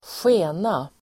Uttal: [²sj'e:na]